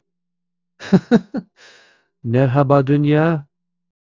Speech Generation - Wiro AI
Text-Prompted Generative Audio Model